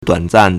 短暂 (短暫) duǎnzàn
duan3zan4.mp3